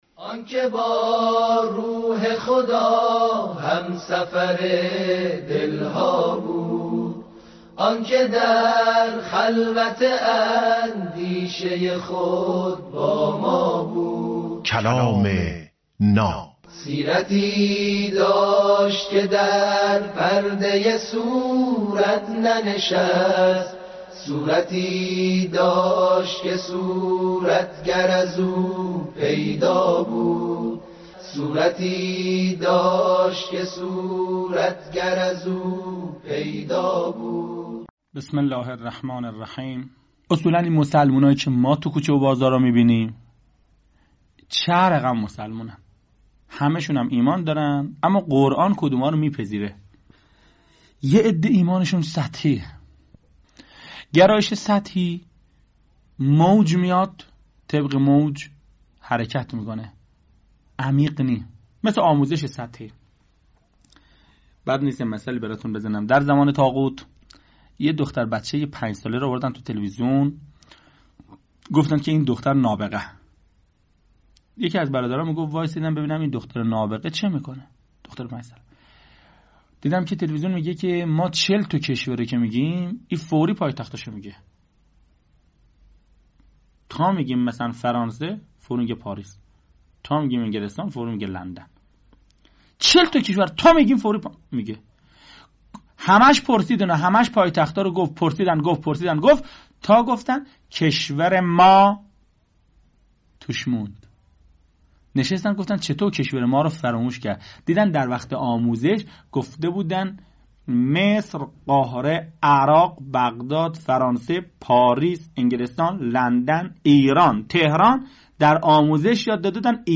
کلام ناب برنامه ای از سخنان بزرگان است که هر روز ساعت 07:08 به وقت افغانستان به مدت 7 دقیقه پخش می شود